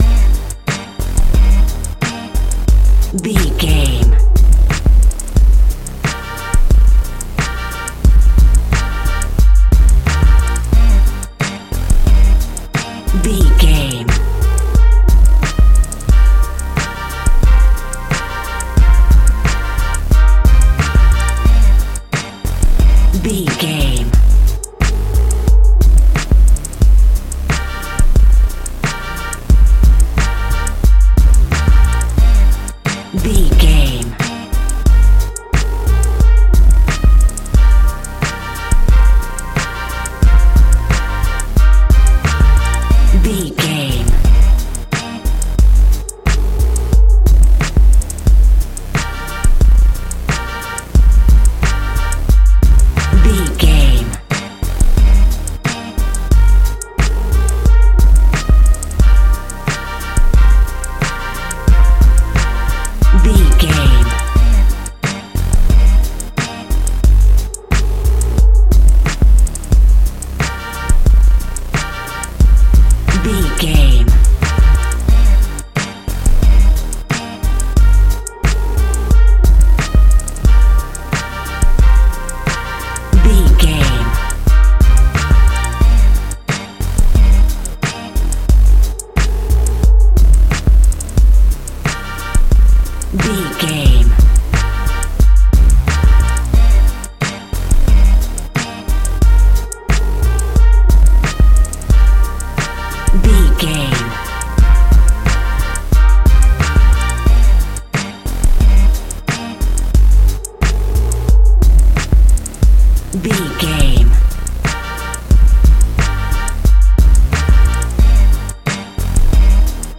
Ionian/Major
drum machine
synthesiser
brass
Funk
funky